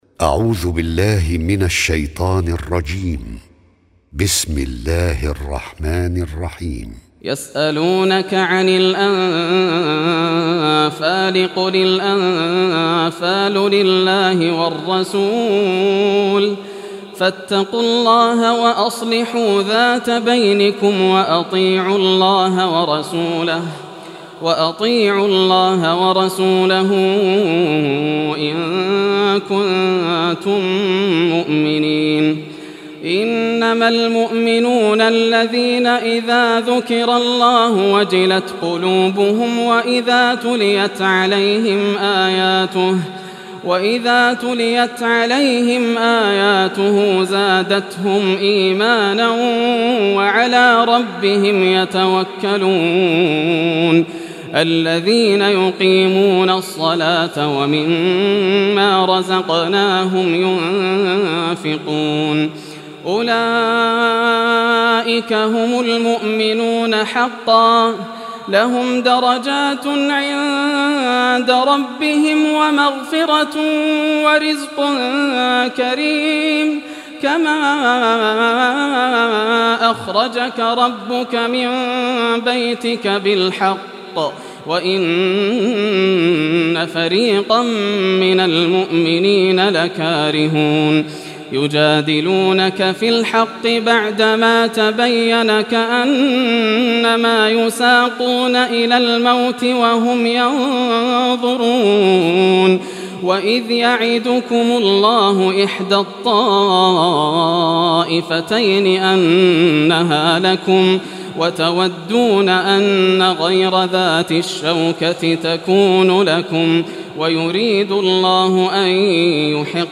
Surah Al-Anfal Recitation by Yasser al Dosari
Surah Al-Anfal, listen or play online mp3 tilawat / recitation in Arabic in the beautiful voice of Sheikh Yasser al Dosari.